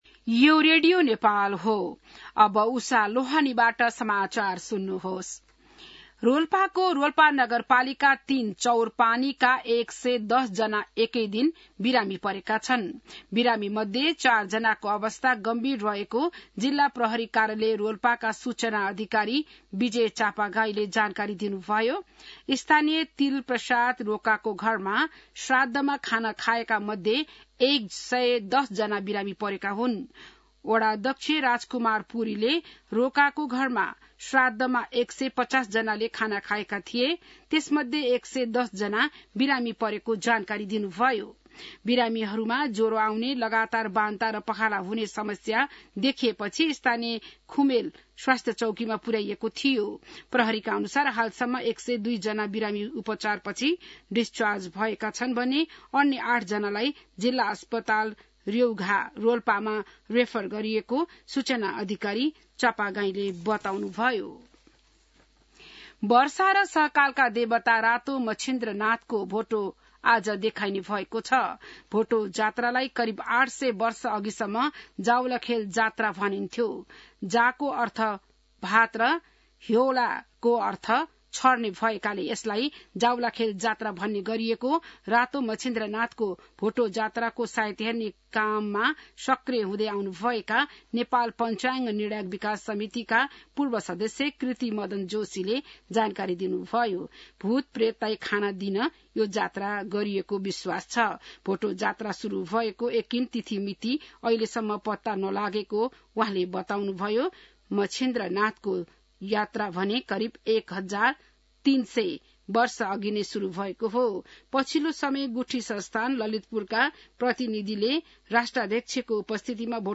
बिहान १० बजेको नेपाली समाचार : १८ जेठ , २०८२